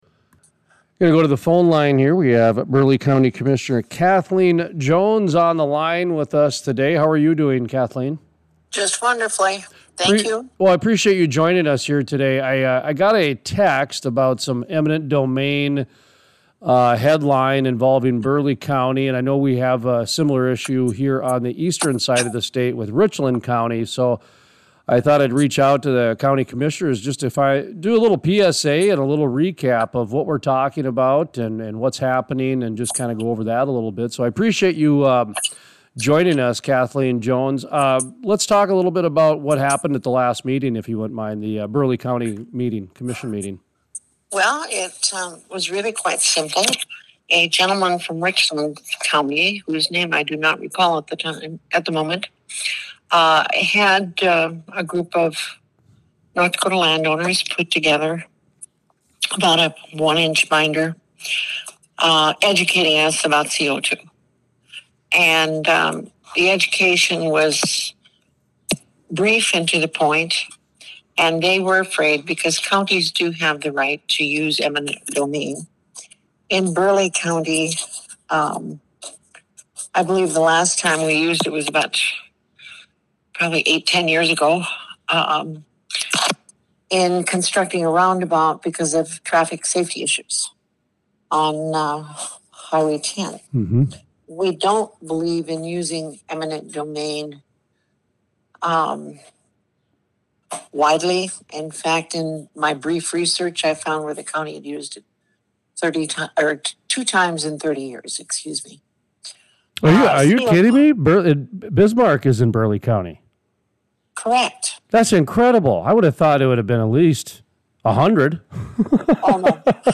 Burleigh County Commission Kathleen Jones talks about the use of eminent domain from governments and how they are used.